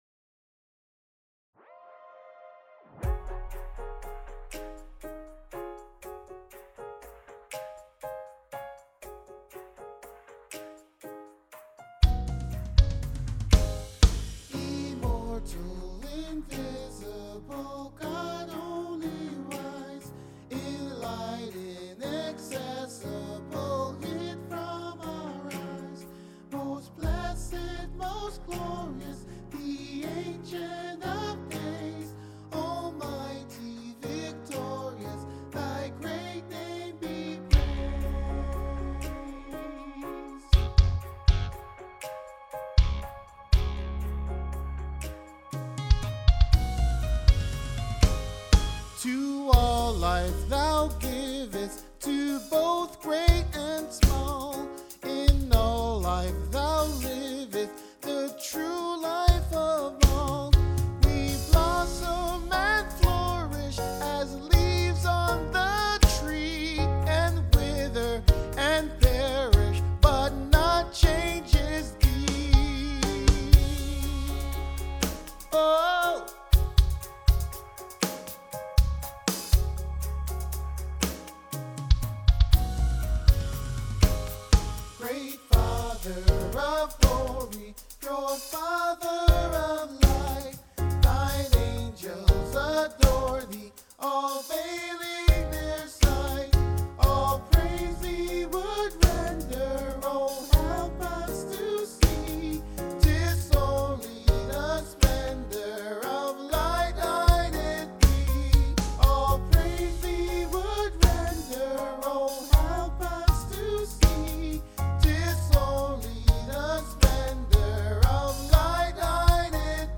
IMMORTAL INVISIBLE GOD : ALTO